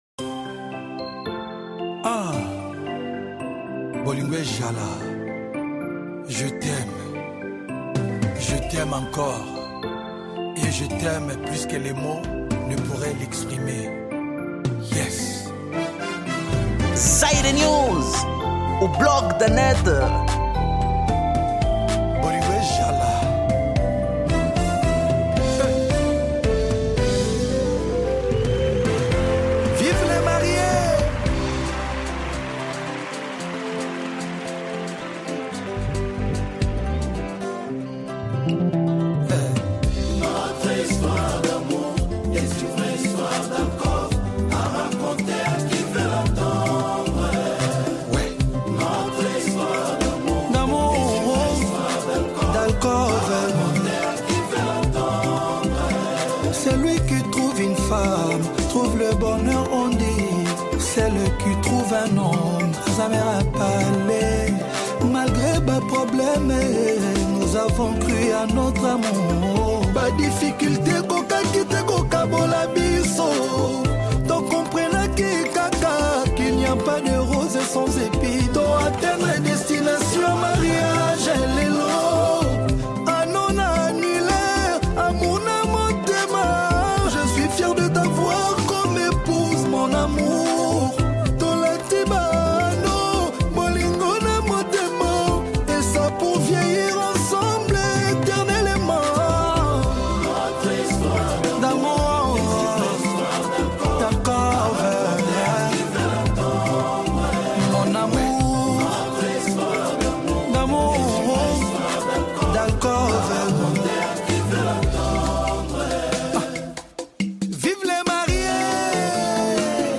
Gênero: Rumba